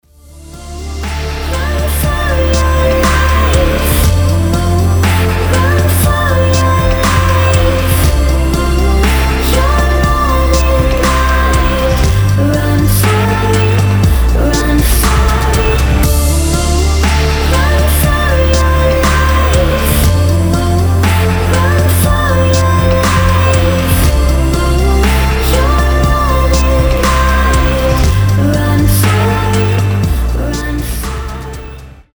Какой-то саундтрек